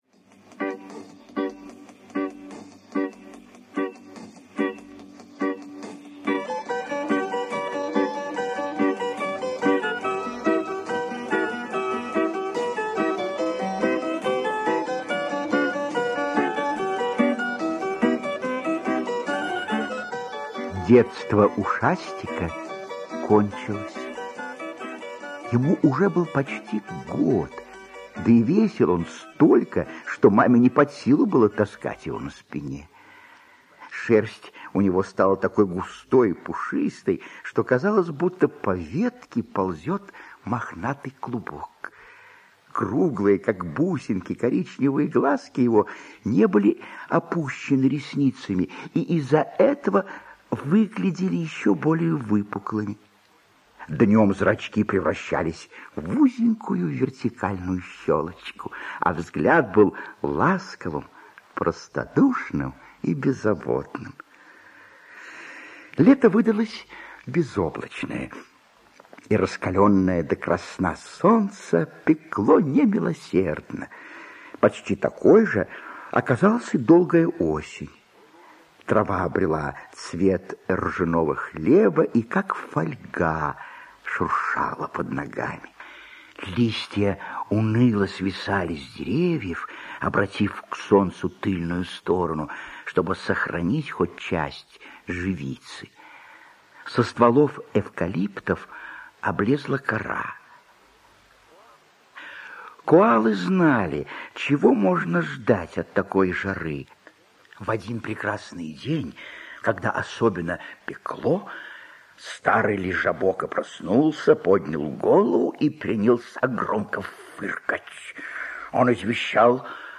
Про коалу Ушастика - аудиосказка Риис - слушать онлайн